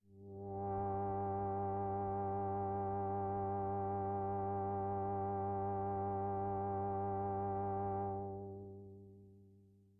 Roland HS80 Vs Pad " Roland HS80 Vs Pad F4 (67 G3SPFE)
标签： MIDI-速度-12 FSharp4 MIDI音符-67罗兰-HS-80 合成器 单票据 多重采样
声道立体声